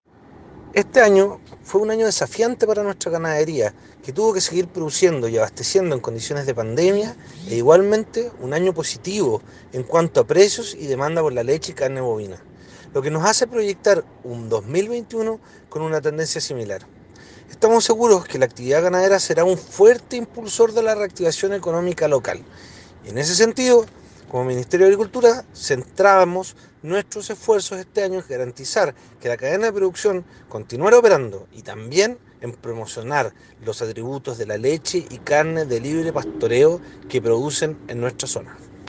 AUDIO-Seremi-de-Agricultura-Eduardo-Winkler.m4a